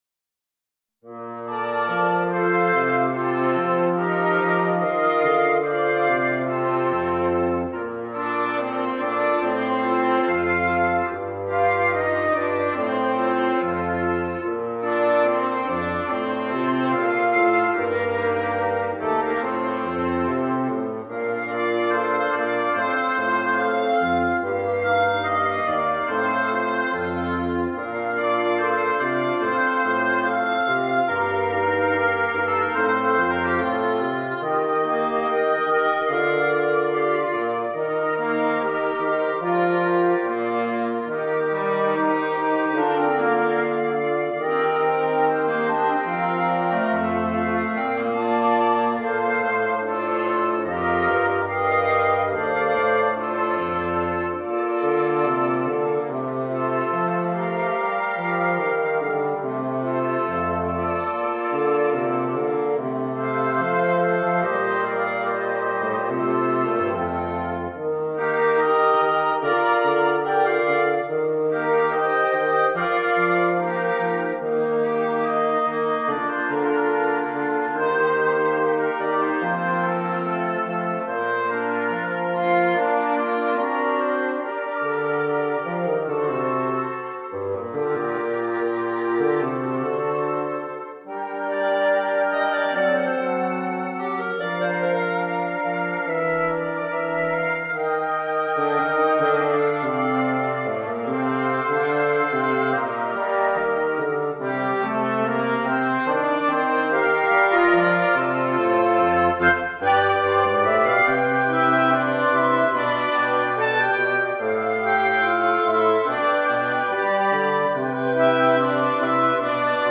(for Woodwind Quintet)
easy arrangement